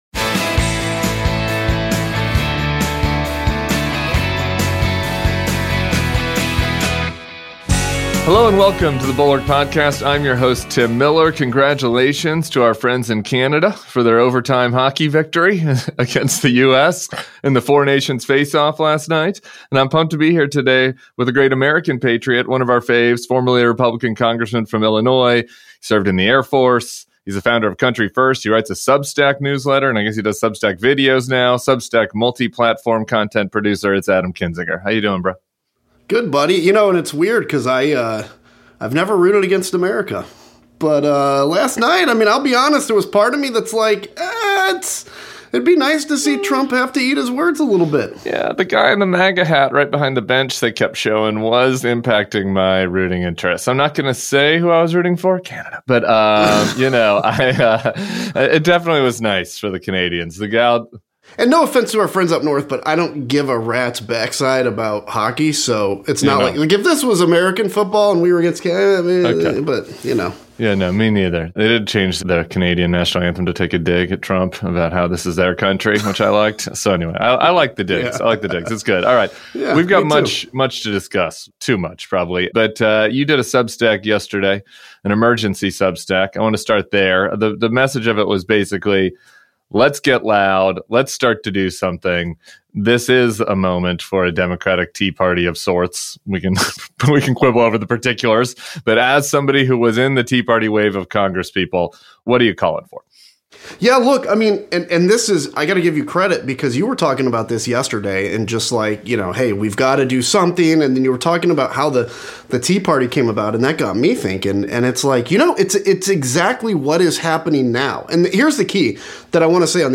Rep. Adam Kinzinger joins Tim Miller for the weekend pod. show notes Tim's interview with CA Democrat Rep.